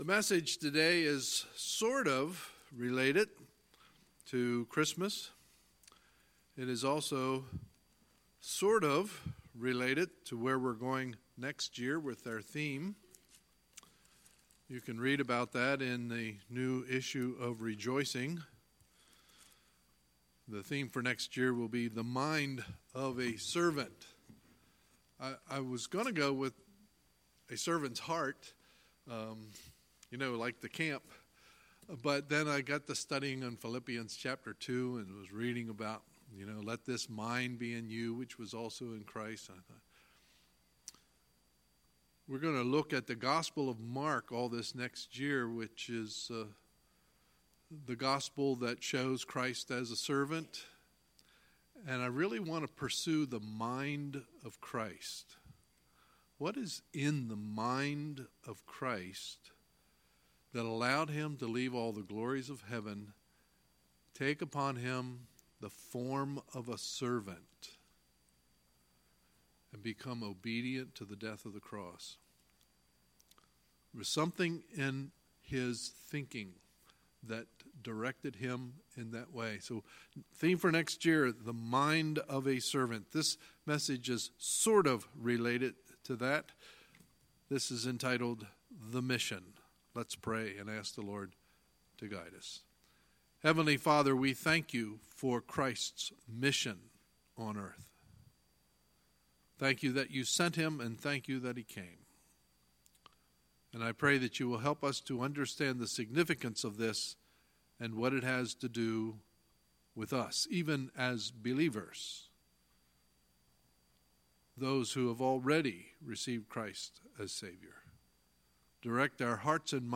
Sunday, December 30, 2018 – Sunday Morning Service